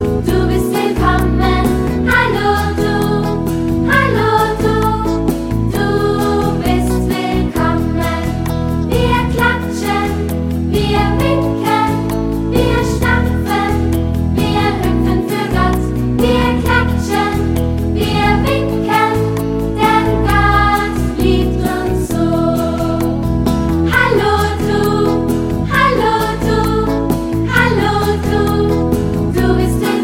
Lieder, Geschichten und Minimusical für Kinder
Kinderlieder